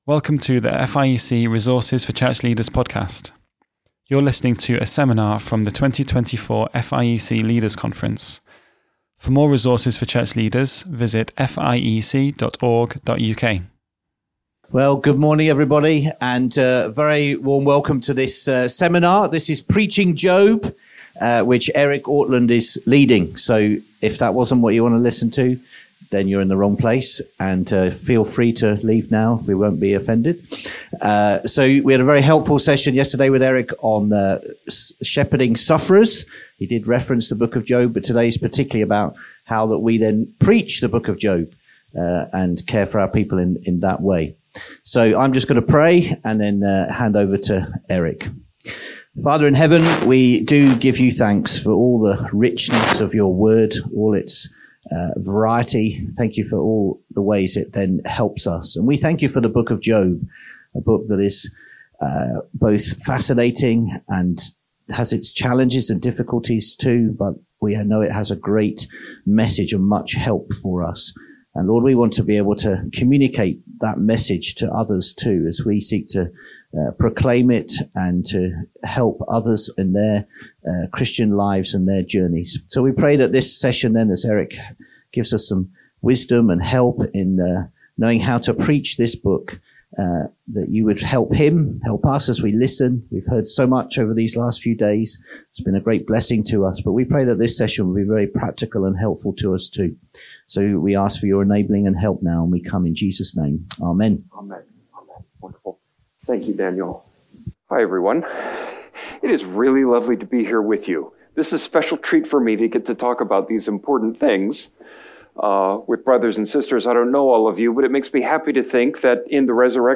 What are the joys and challenges of preaching through Job? A seminar from the 2024 Leaders' Conference.